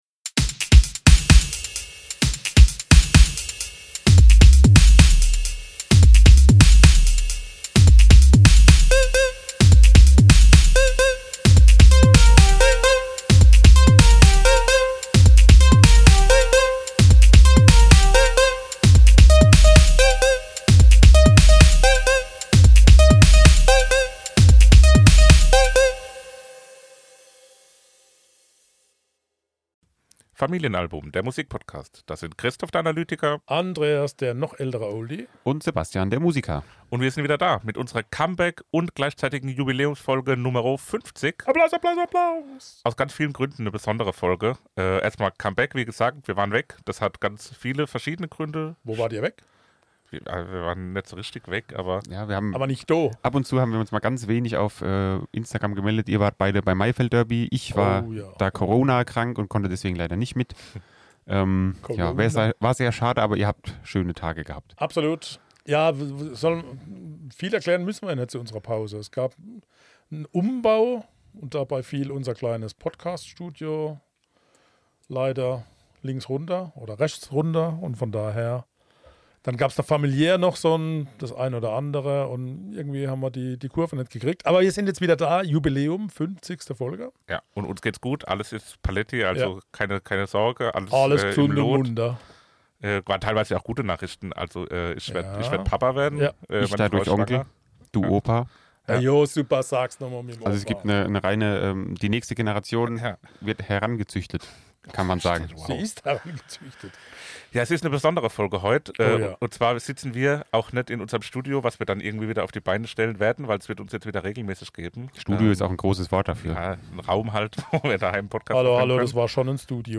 Folge 50 – Interview mit Drangsal zu "Exit Strategy"
folge-50-interview-mit-drangsal-zu-exit-strategy-mmp.mp3